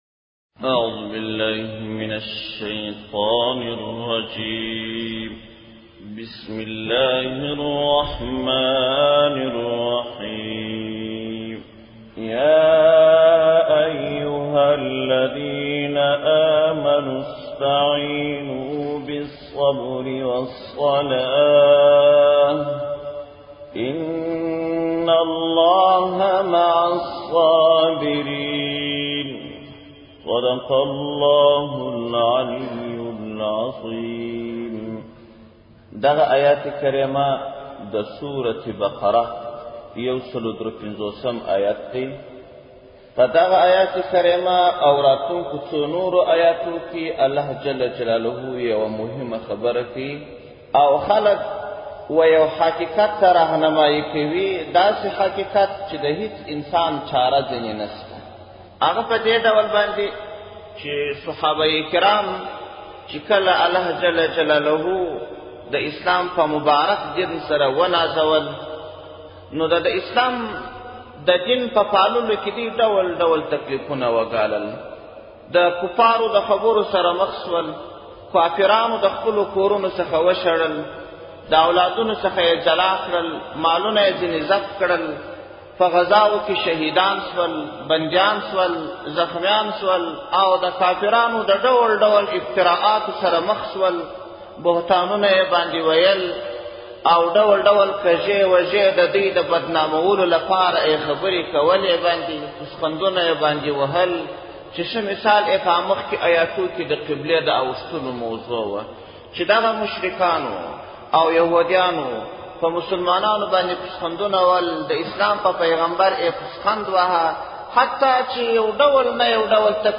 جولای 24, 2016 تفسیرشریف, ږغیز تفسیر شریف 1,254 لیدنی